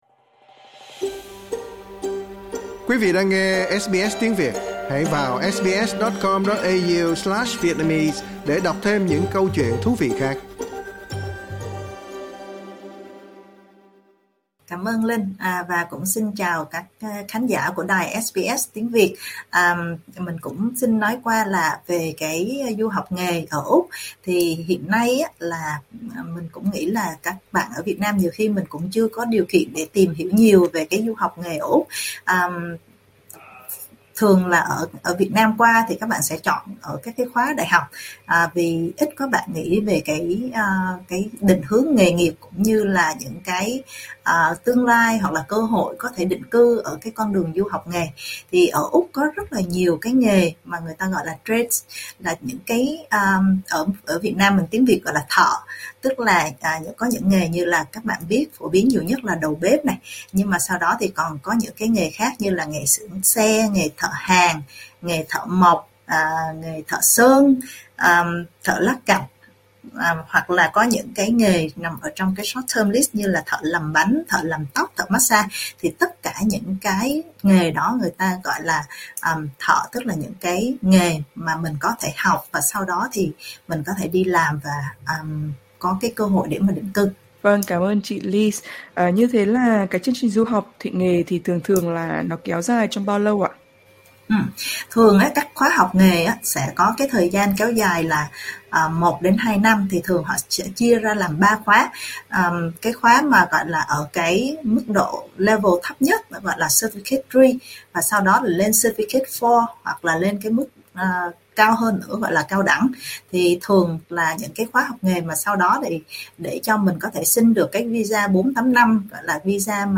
SBS Tiếng Việt trò chuyện